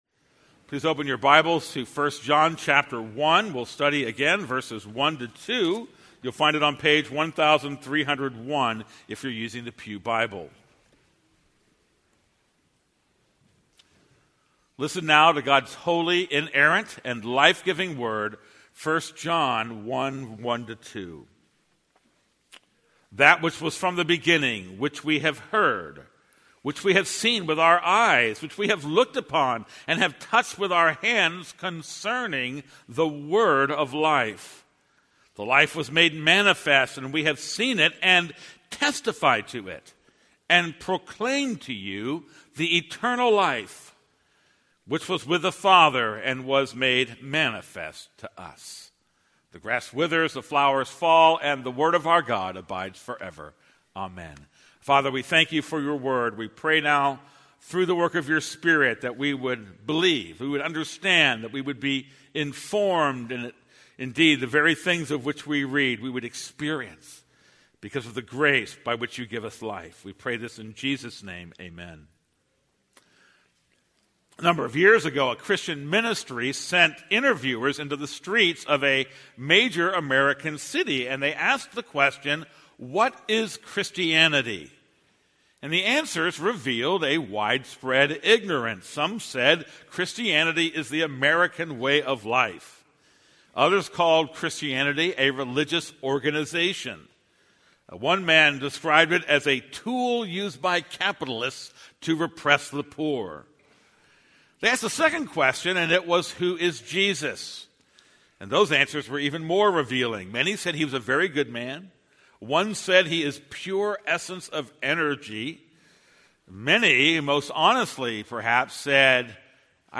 This is a sermon on 1 John 1:1-2.